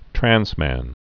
(trănsmăn)